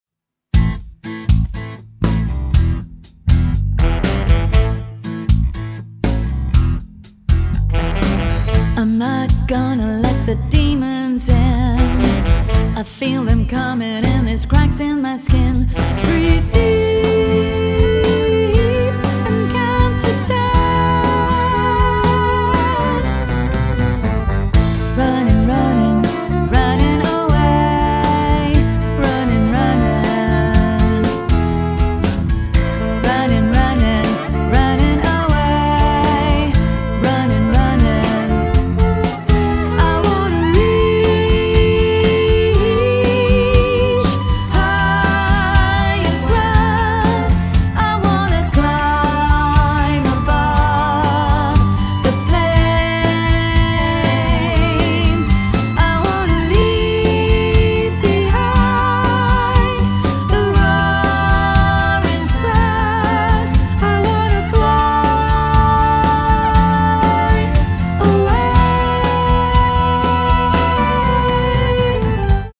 A prog rock number